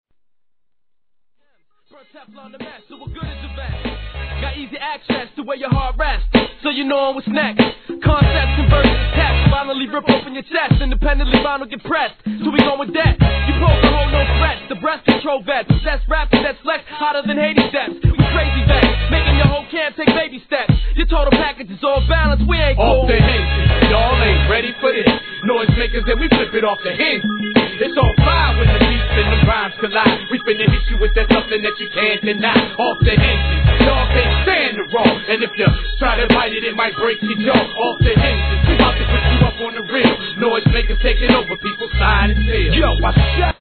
G-RAP/WEST COAST/SOUTH
力強いフォーンのミニマムサンプリングループに、フィメールラッパーを含む個性派揃いのMICリレーが印象的な激ローカル物!!